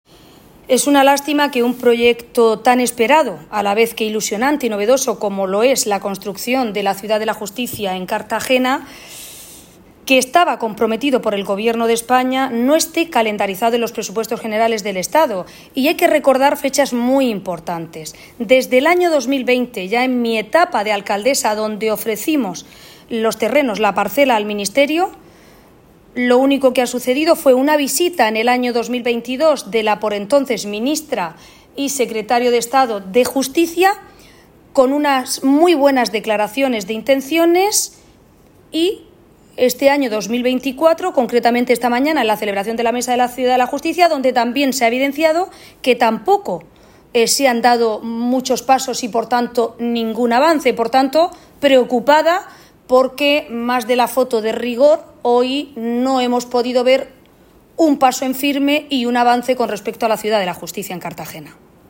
Audio: Declaraciones de Ana Bel�n Castej�n. (MP3 - 619,21 KB)